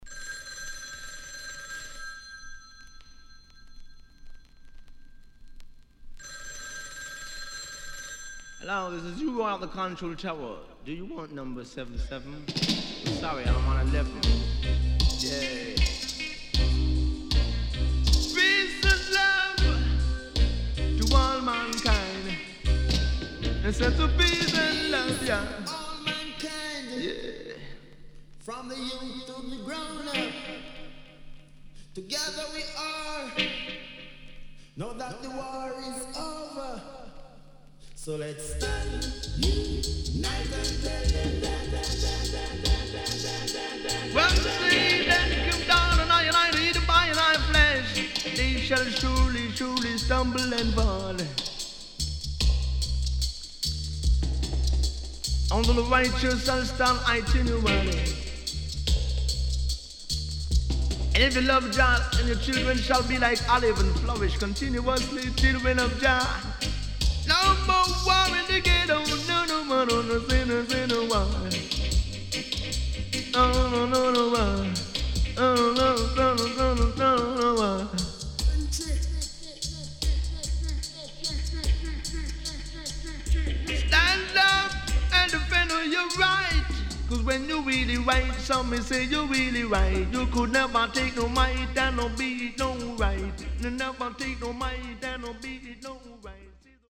往年の名曲の数々にTalk Overした傑作盤
SIDE A:少しチリノイズ入りますが良好です。